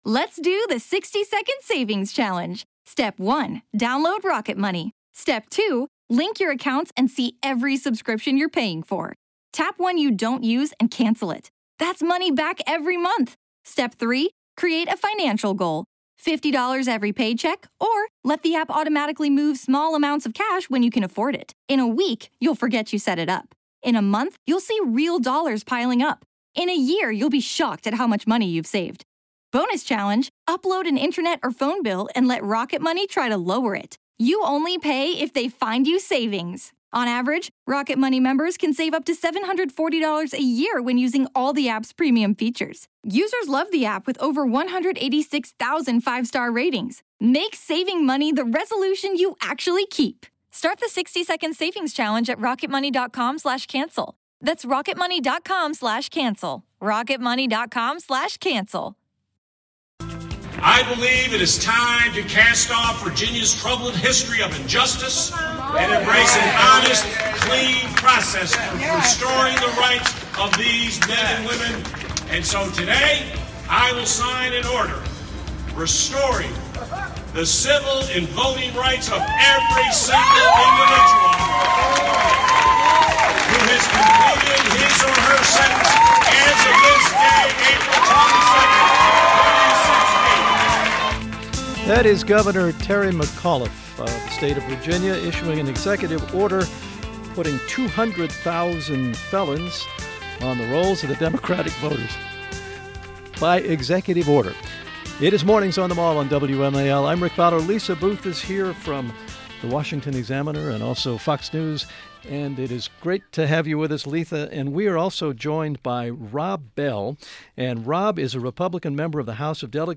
WMAL Interview - VA DEL. ROB BELL - 06.20.16
INTERVIEW – ROB BELL – Republican Member of the House of Delegates, representing the 58th district in the Virginia Piedmont including Greene County and parts of Albemarle, Fluvanna and Rockingham Counties.